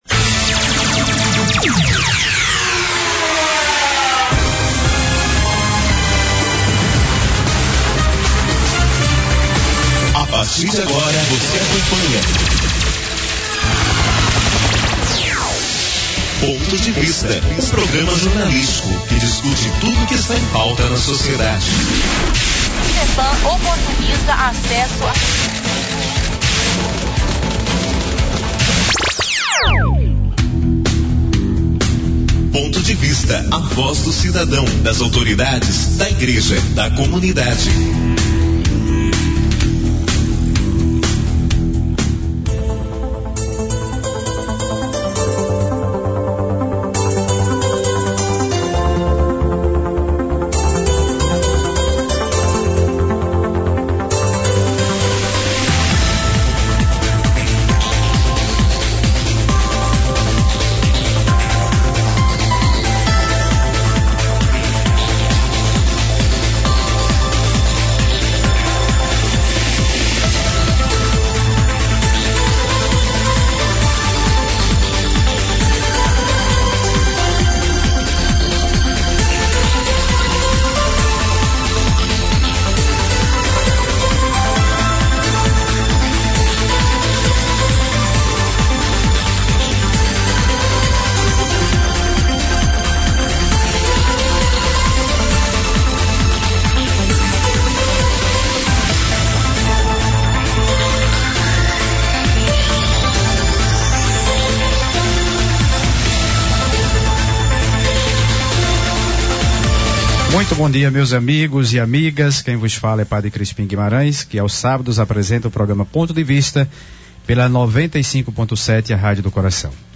Entrevista com Eleandro Passaia.